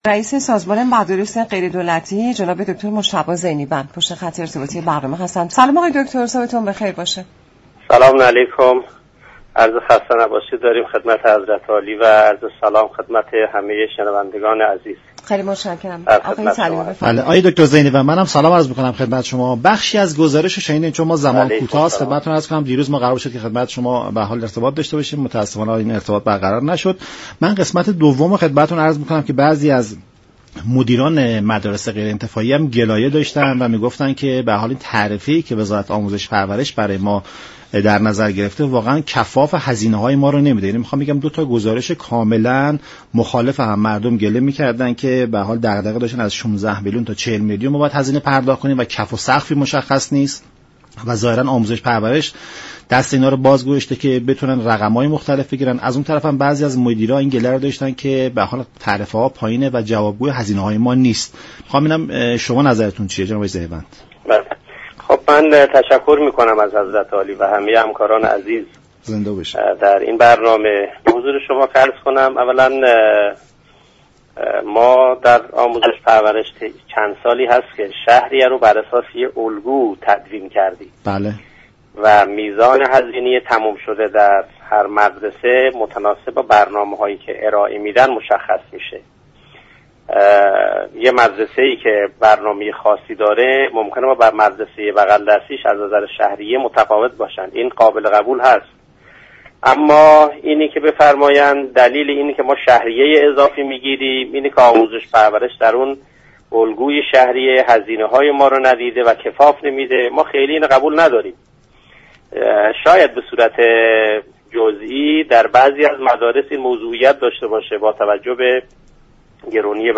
معاون وزیر آموزش و پرورش در گفت و گو با برنام نمودار رادیو ایران گفت: در الگوی ارائه شده وزارت آموزش و پرورش به دغدغه های مدیران مدارس توجه شده است.